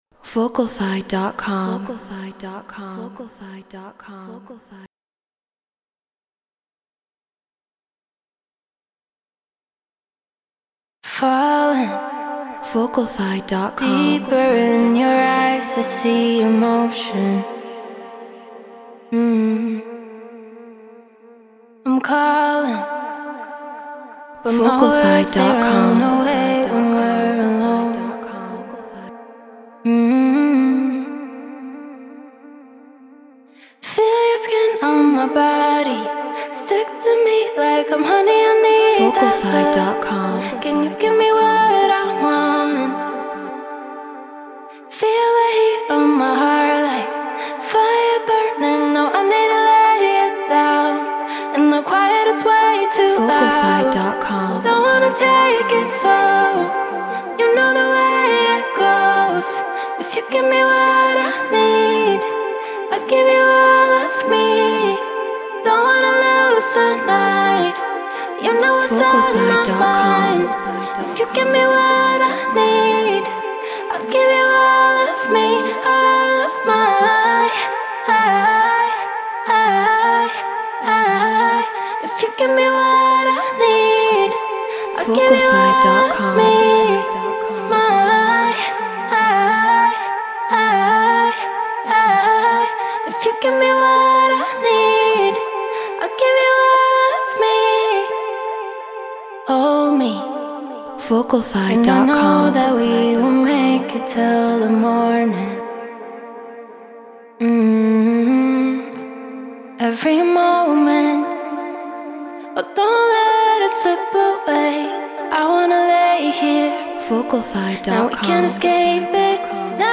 Drum & Bass 174 BPM Gmin
Shure KSM 44 Apollo Twin X Pro Tools Treated Room